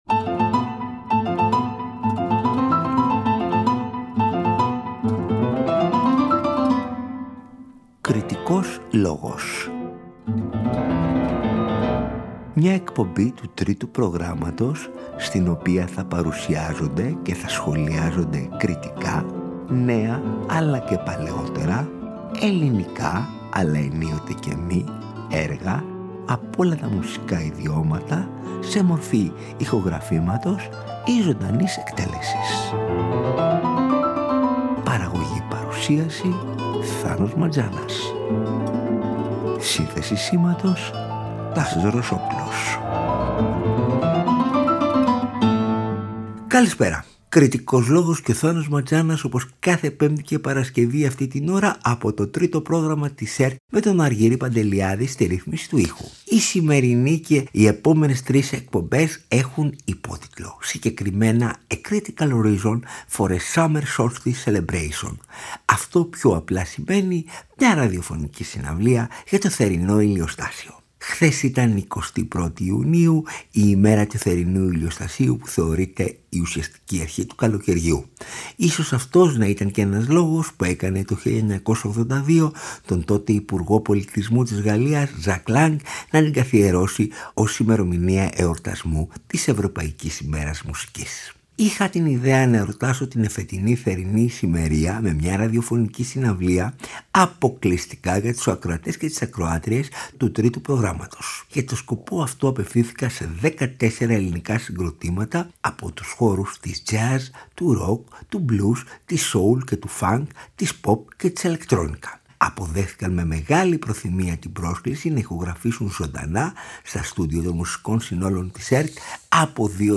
Μια ραδιοφωνική συναυλία για το θερινό ηλιοστάσιο
It’s (summer) gig time…enjoy the live music!